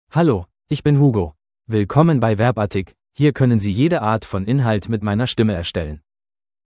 HugoMale German AI voice
Hugo is a male AI voice for German (Germany).
Voice sample
Listen to Hugo's male German voice.
Hugo delivers clear pronunciation with authentic Germany German intonation, making your content sound professionally produced.